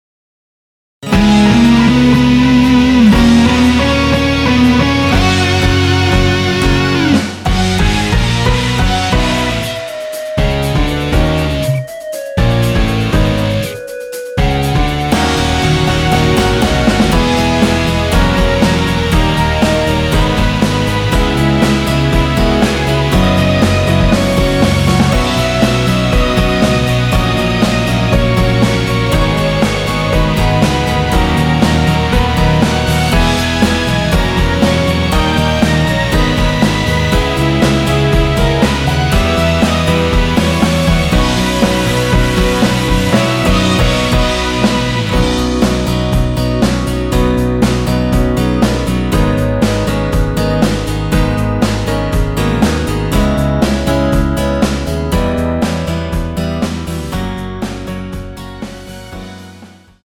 원키에서(-1)내린 멜로디 포함된 MR 입니다.(미리듣기 참조)
앞부분30초, 뒷부분30초씩 편집해서 올려 드리고 있습니다.